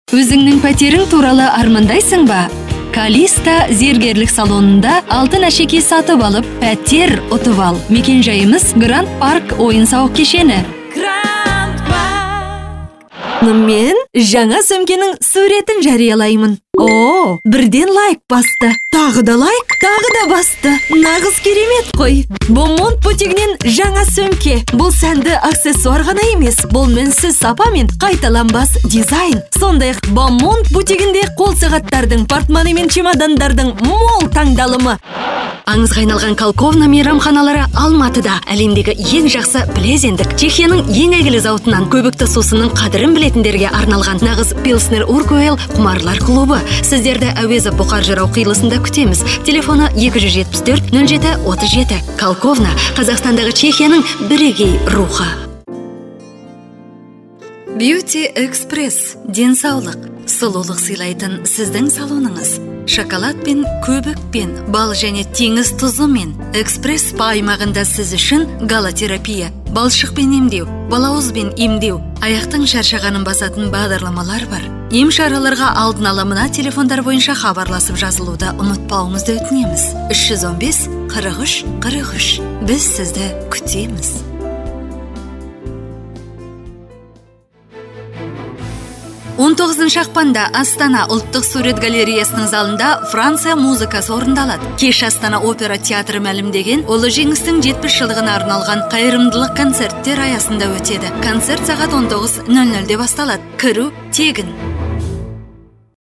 Теледидар жүргізушілерінің дауыстары
Әйелдер